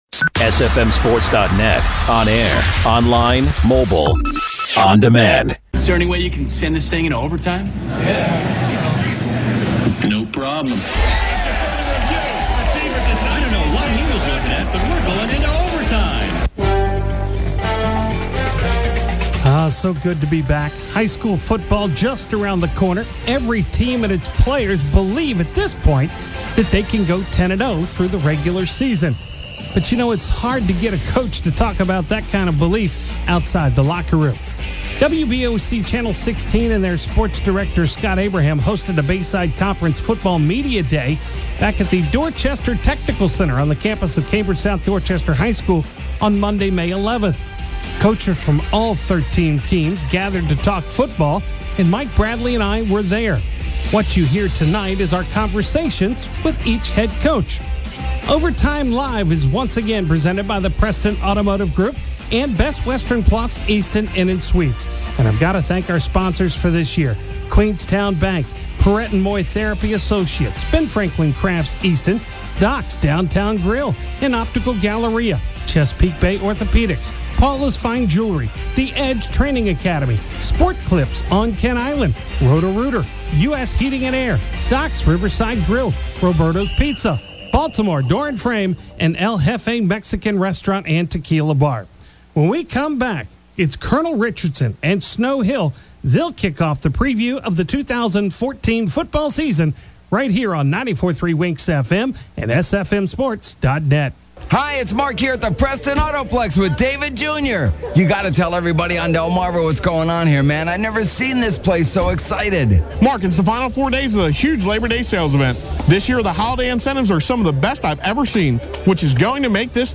preview the 2014 Bayside Conference Football season and chat with the head coaches on Overtime Live.